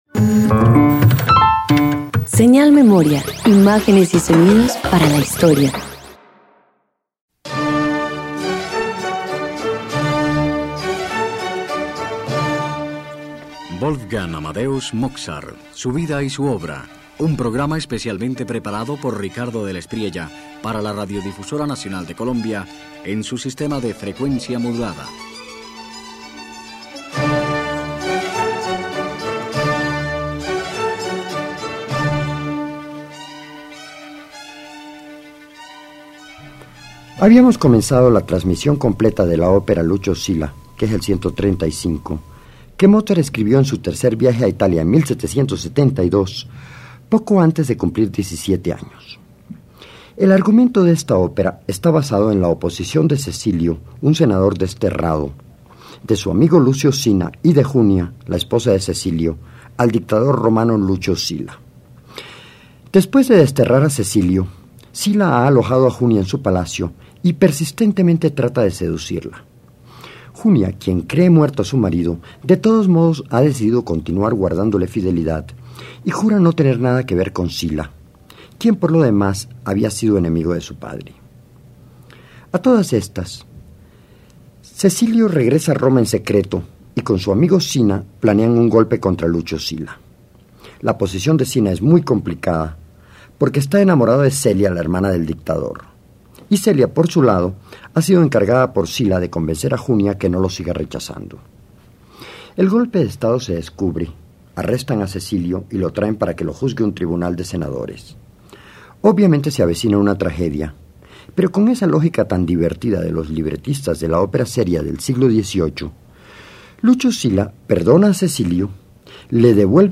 071 Opera Lucio Silla Parte V_1.mp3